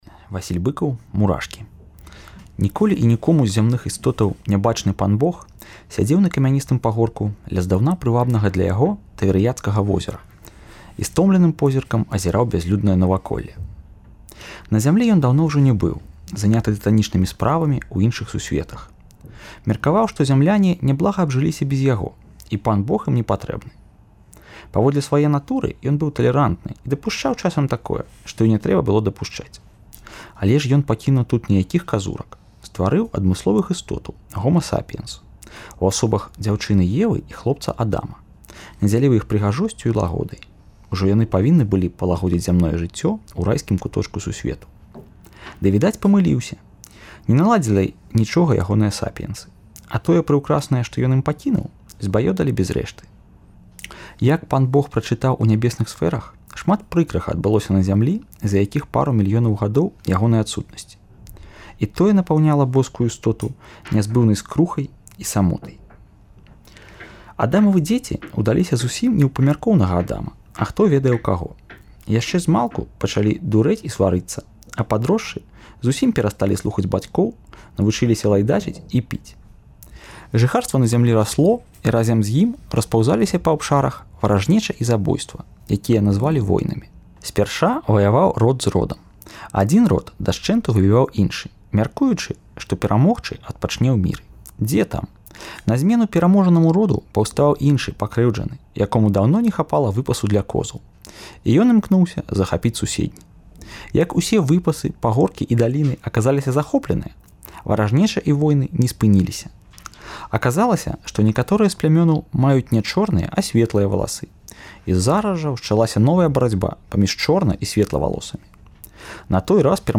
Штодня ў чэрвені выпускнікі Беларускага гуманітарнага ліцэю (цяперашнія і колішнія) чытаюць радкі з улюблёных быкаўскіх твораў.